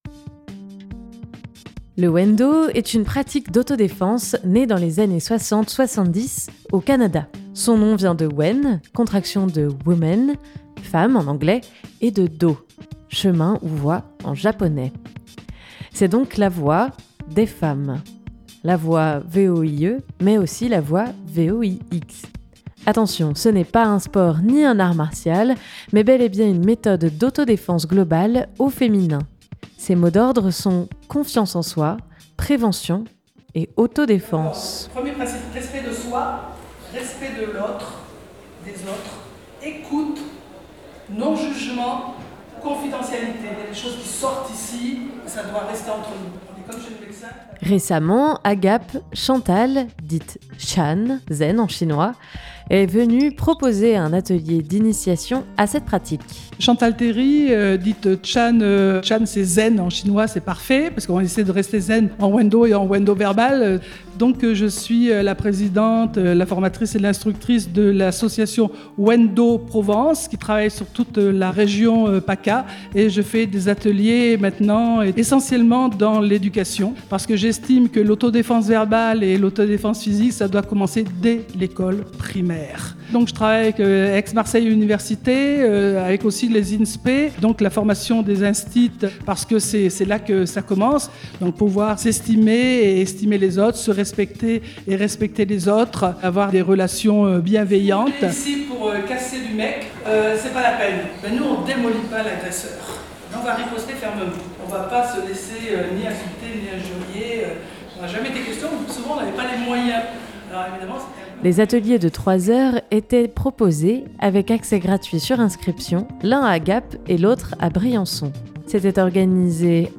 L'occasion de comprendre les mécanismes de violence non physique, et de s'initier à l'auto-défense verbale. Un moment dense, mais non sans des pointes d'humour. 250515 - atelier Wendo du 2...